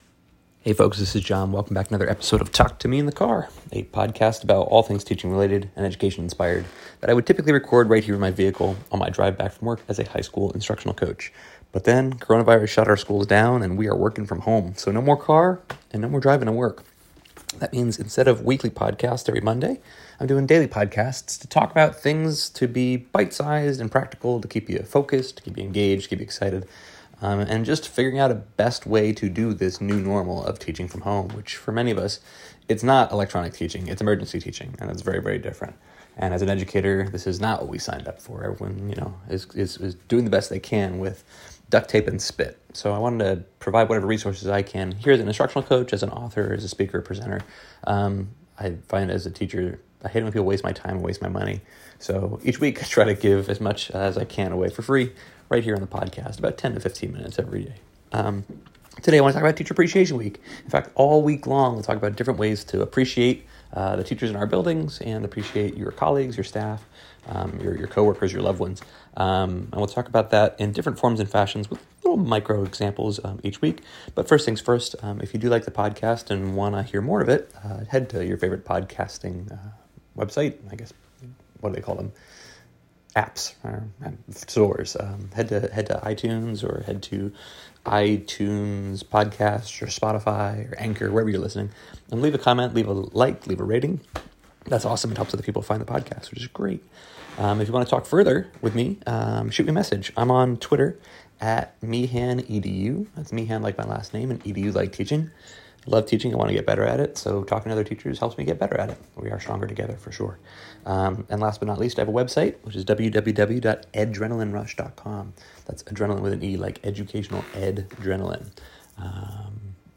Recorded in bumper-to-bumper traffic to and from my daily work as a high school instructional coach just outside of Washington D.C., Talk to Mee in the Car is where a brainstorm and a good old-fashioned brain dump collide to help educators hone their skills and become leaner, meaner, 21st century teaching machines.